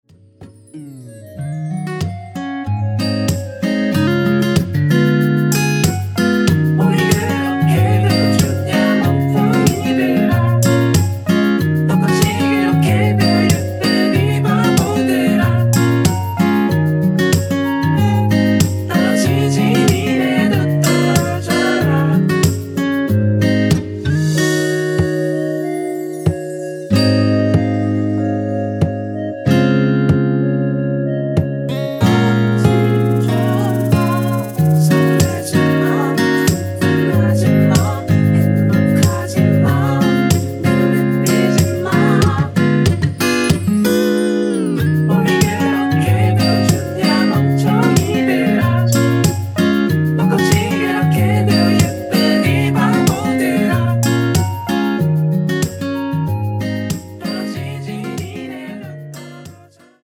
원키 멜로디와 코러스 포함된 MR 입니다.(미리듣기 확인)
Db
앞부분30초, 뒷부분30초씩 편집해서 올려 드리고 있습니다.
중간에 음이 끈어지고 다시 나오는 이유는